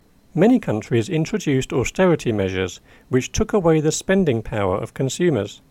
DICTATION 3